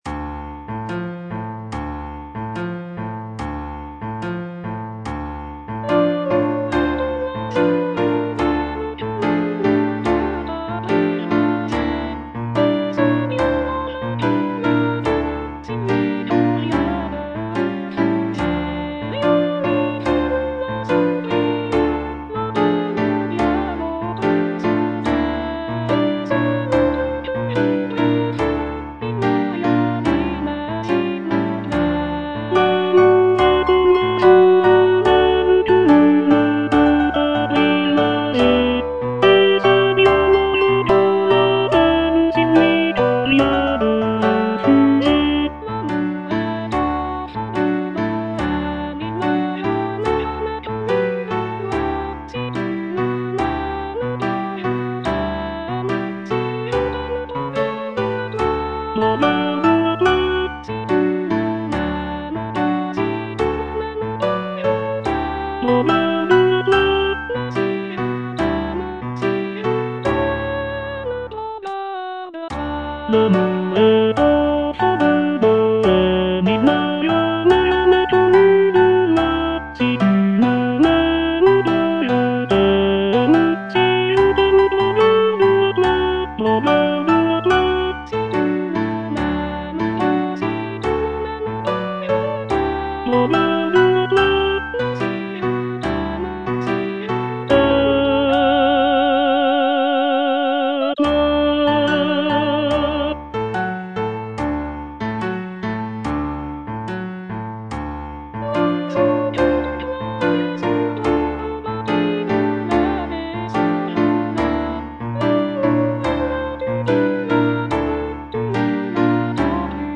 G. BIZET - CHOIRS FROM "CARMEN" Habanera (tenor I) (Voice with metronome) Ads stop: auto-stop